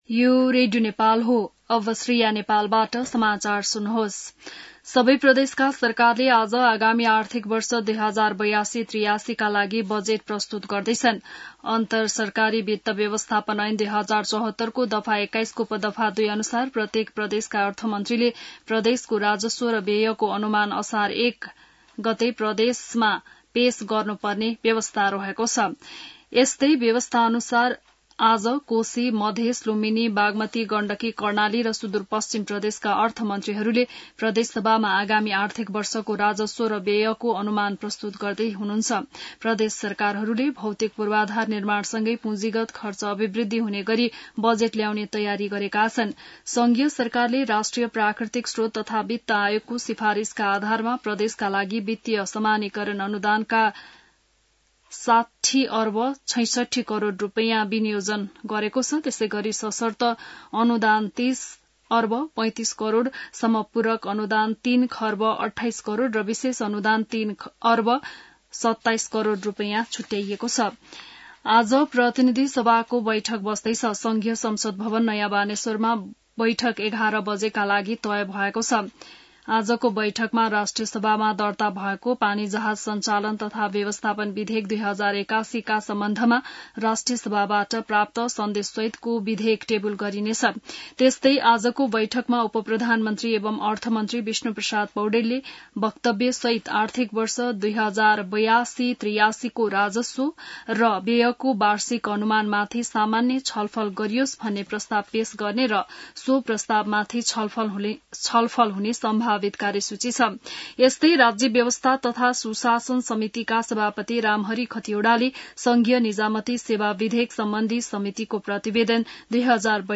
बिहान १० बजेको नेपाली समाचार : १ असार , २०८२